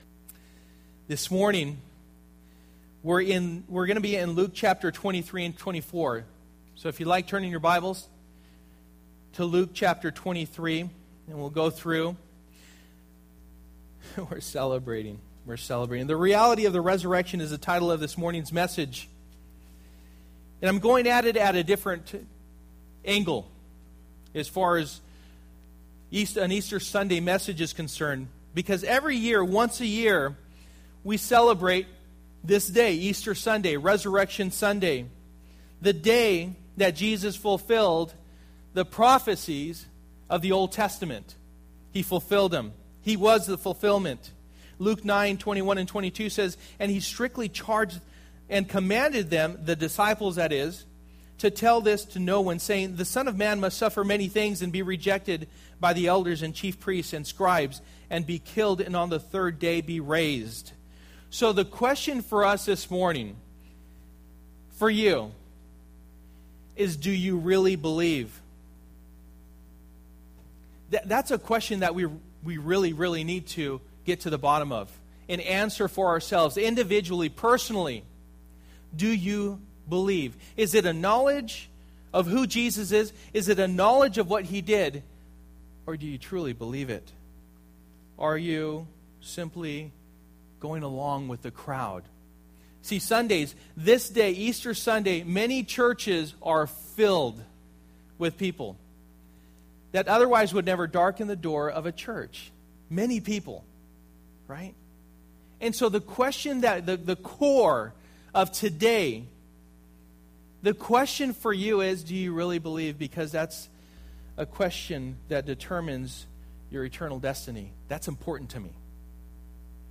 Easter Message 2013